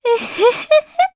Mediana-Vox_Happy1_jp_b.wav